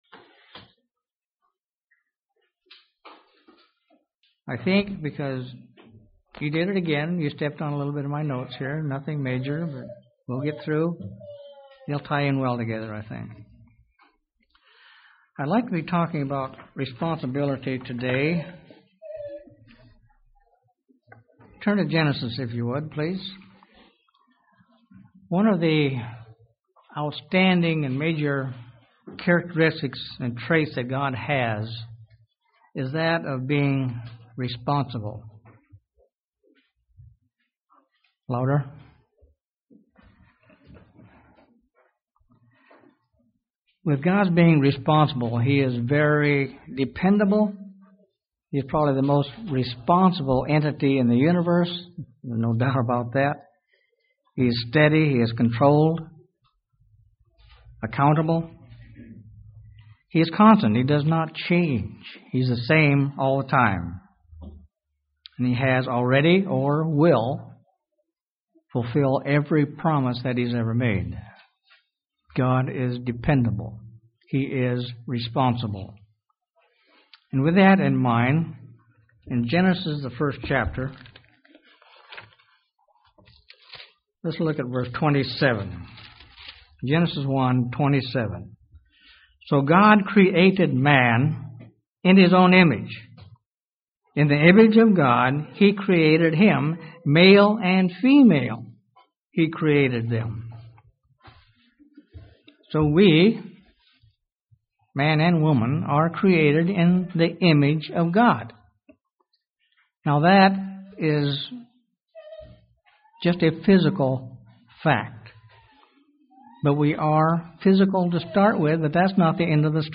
Given in Huntsville, AL
UCG Sermon Studying the bible?